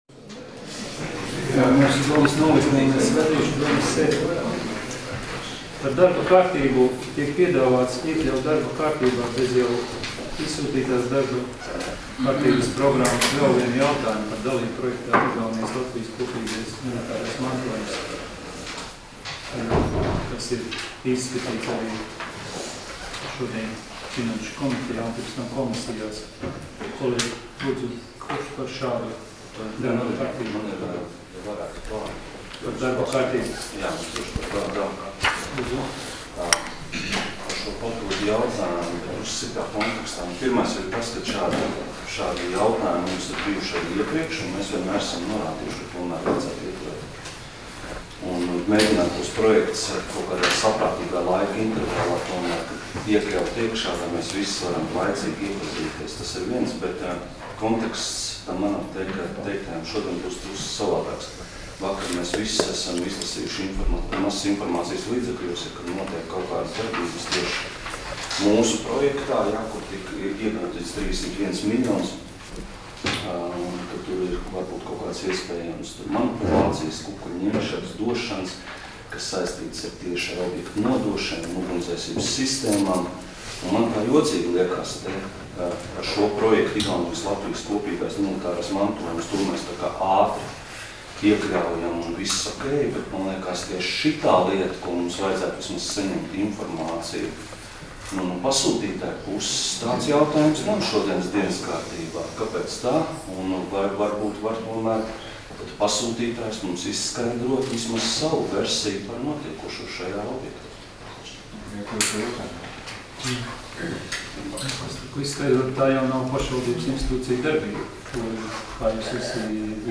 Domes sēdes 24.05.2019. audioieraksts